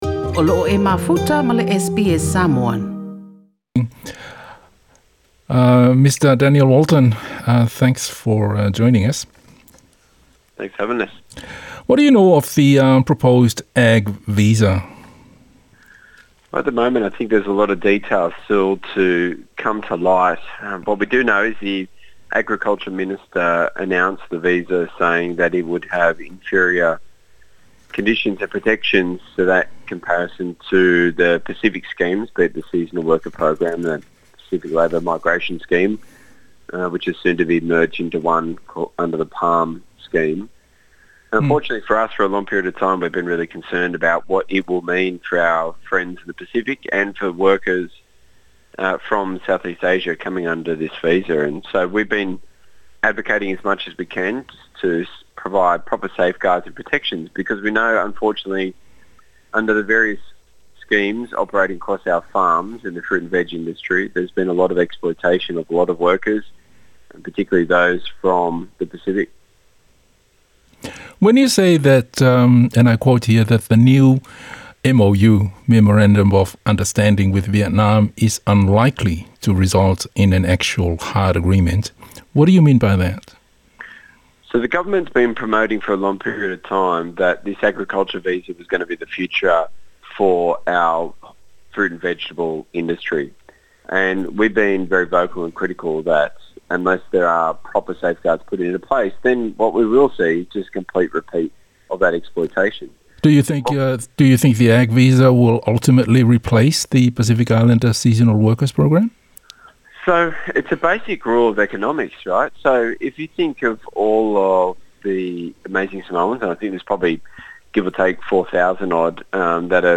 (O le talanoaga lenei o loo faia i le gagana Peretania).